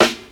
• Nineties Warm Hip-Hop Snare Sample F Key 17.wav
Royality free snare sample tuned to the F note. Loudest frequency: 1809Hz
nineties-warm-hip-hop-snare-sample-f-key-17-Vnm.wav